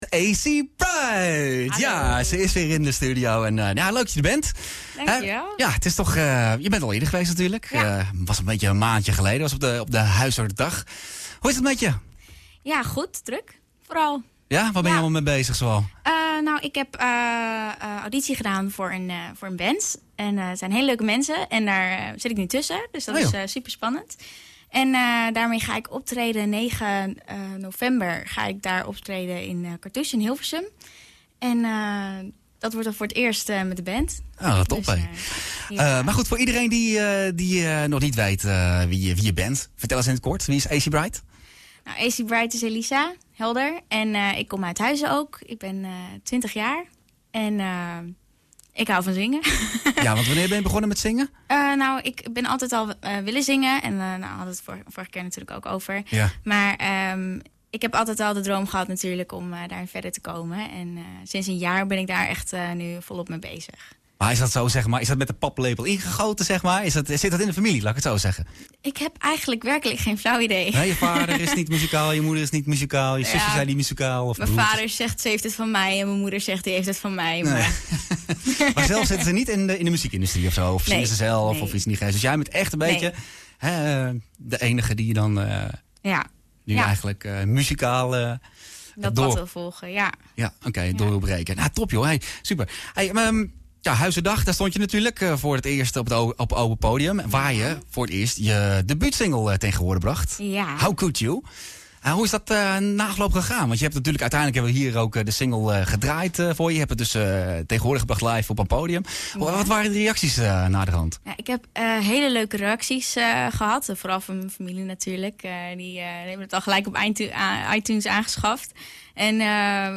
Check hier het interview en haar live performance.